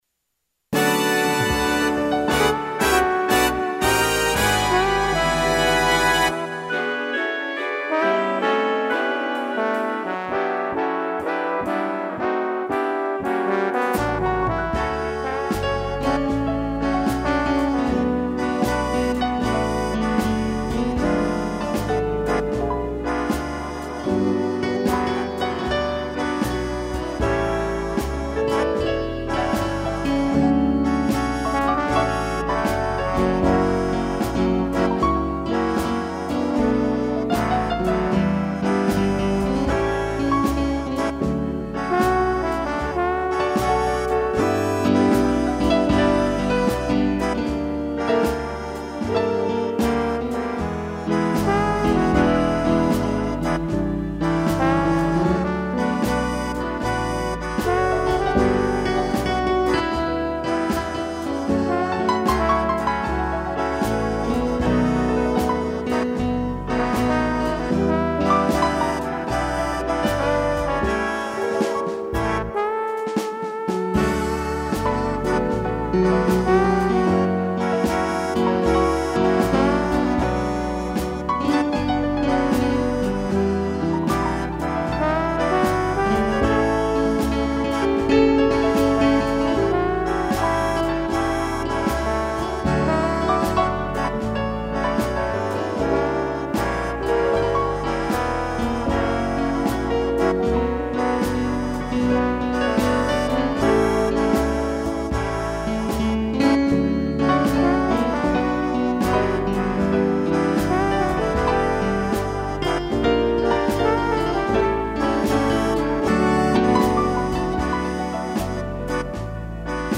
piano e trombone
(instrumental)